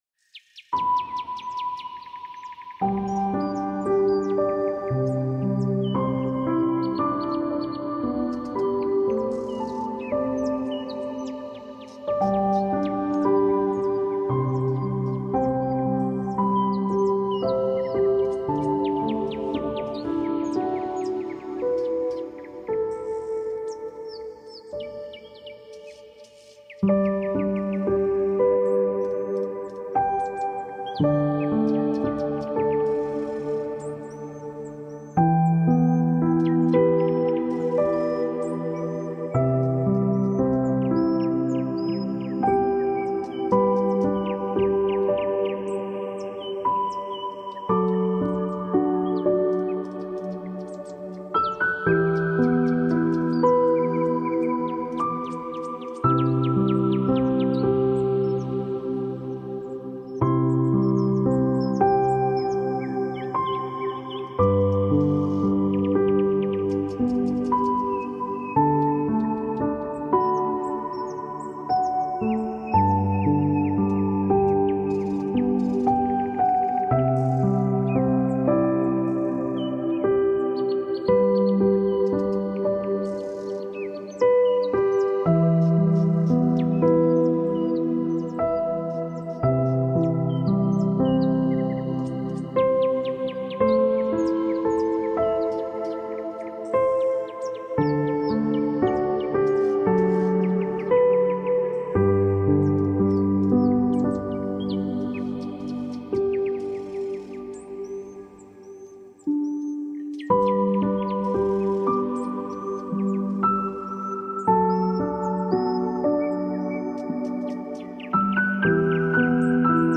Musica-de-Curacion-con-Ondas-Positivas.mp3